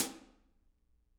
R_B Hi-Hat 05 - Close.wav